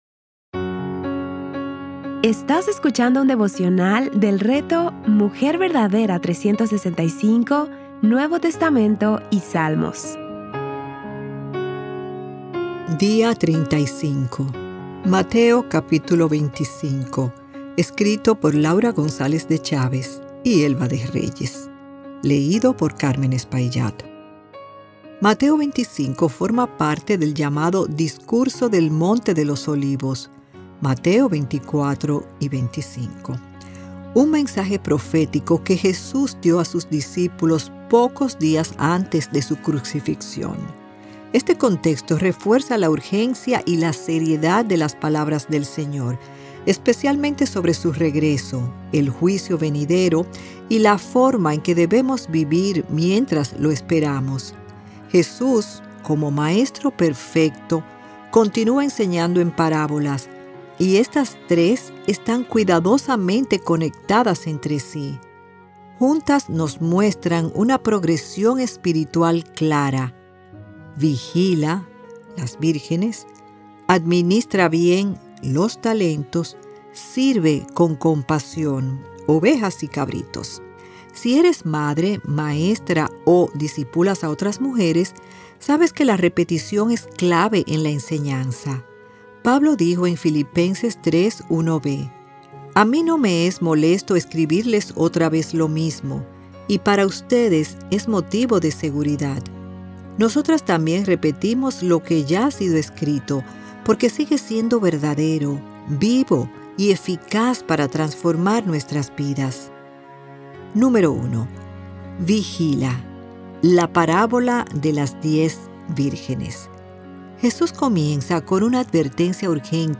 Series:  Mateo y Salmos | Temas: Lectura Bíblica